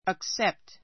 əksépt ア ク セ プト